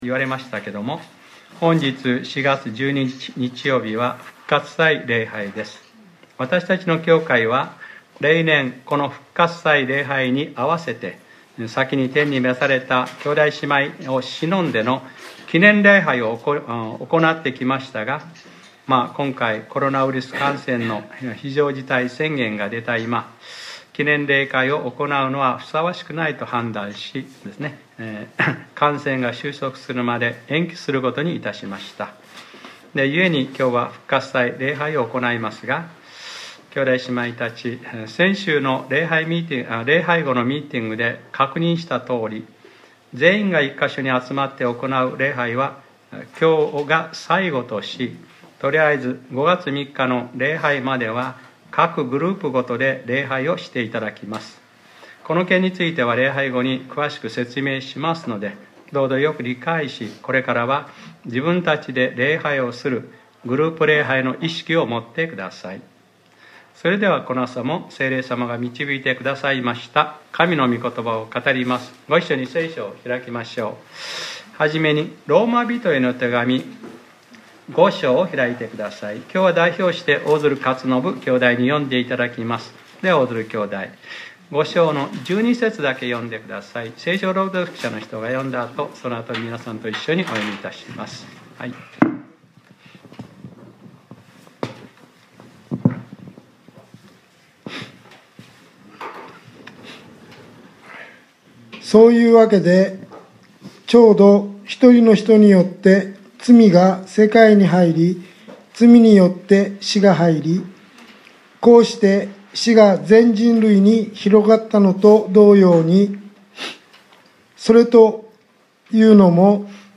2020年04月12日（日）礼拝説教『この男はまるでペストのような存在で』